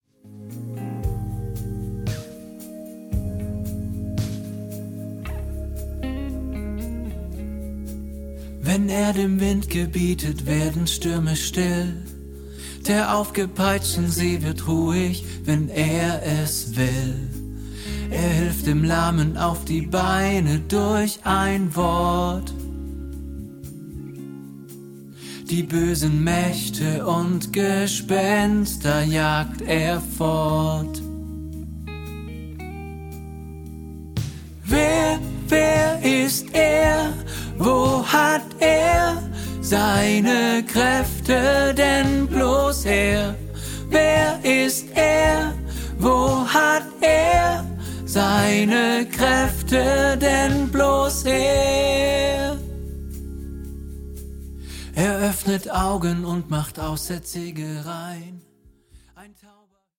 Separate Aufnahmen mit hervorgehobenen Tenor-Stimmen.